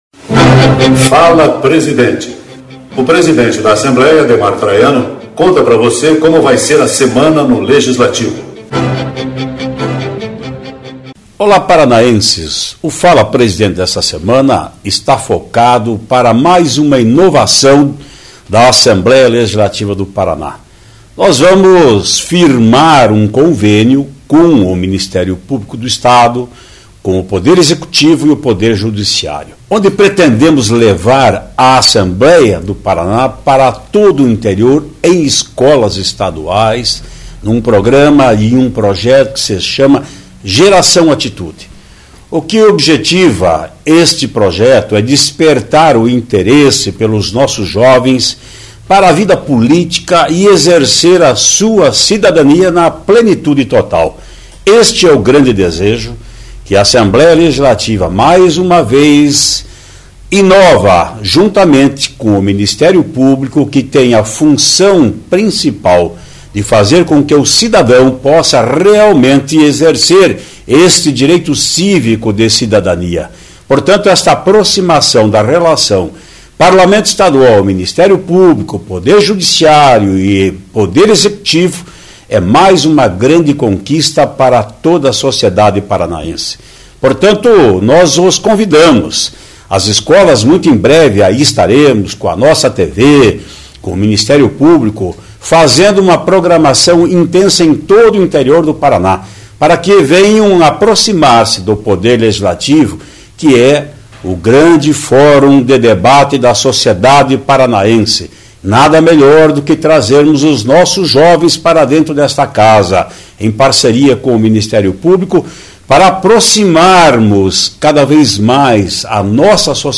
Ouça o que o presidente Ademar Traiano tem a dizer a você sobre as principais notícais do Legislativo esta semana.